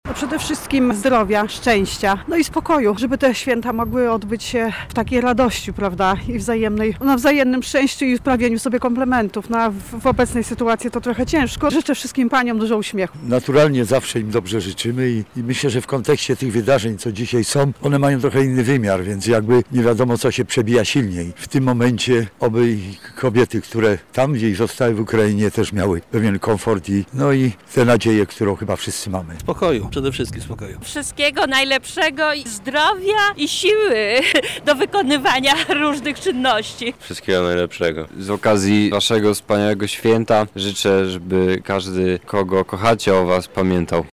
[SONDA] Czego mieszkańcy Lublina życzą kobietom w ich święto?
Nasz reporter zapytał mieszkańców Lublina, czego życzą paniom z okazji ich święta:
SONDA